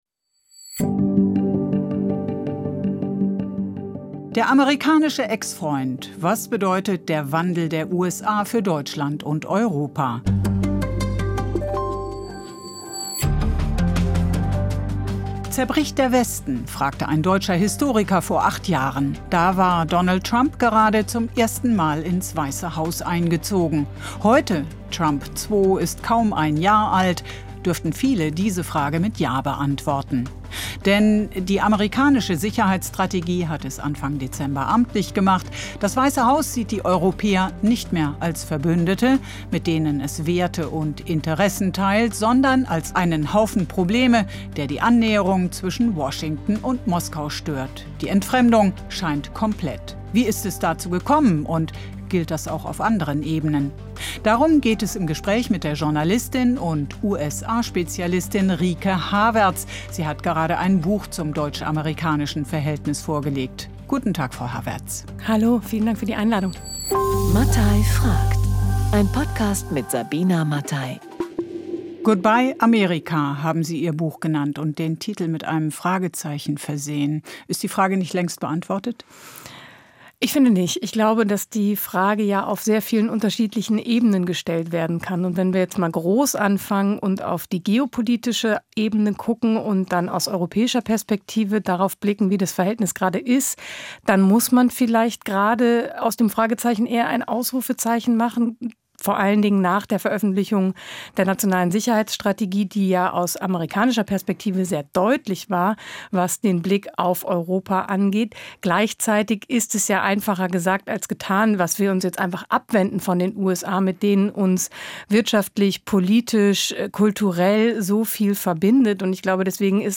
die Journalistin und USA-Spezialistin